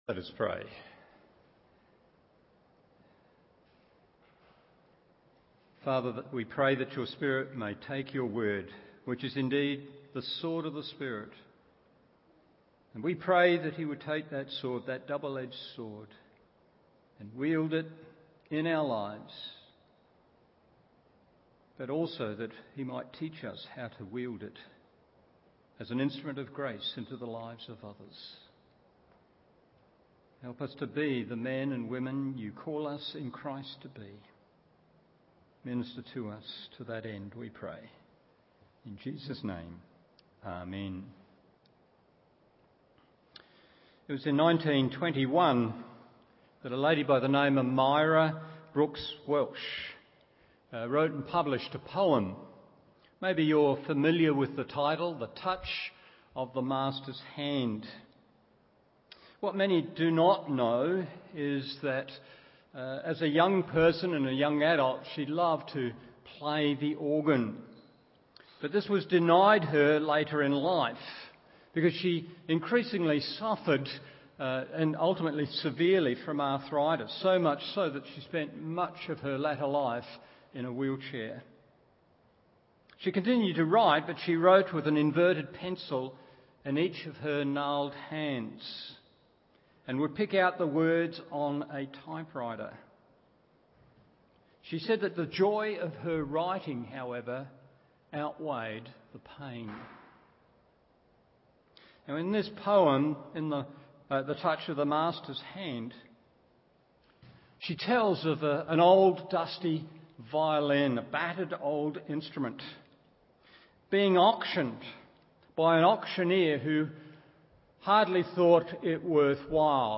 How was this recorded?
Evening Service Col 4:1-14 1. Disposition 2. Dependability 3.